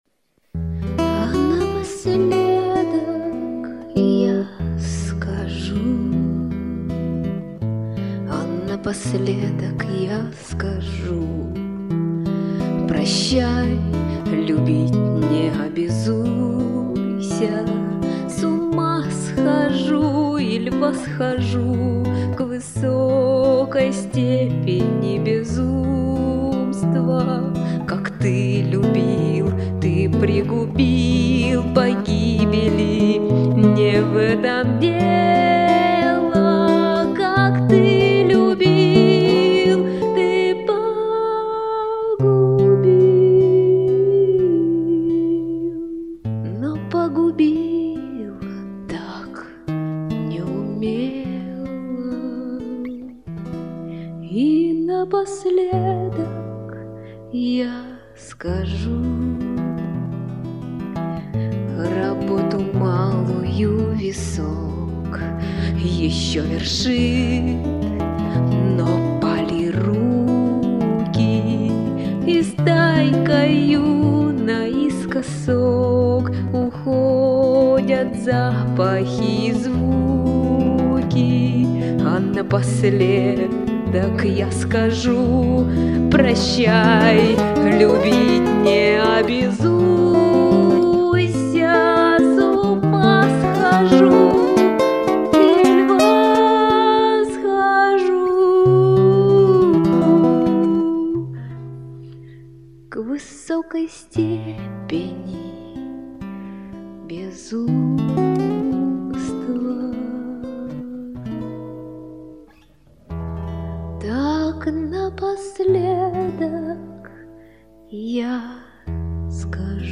Значит поём,может быть кто -то услышит как поют две женщины.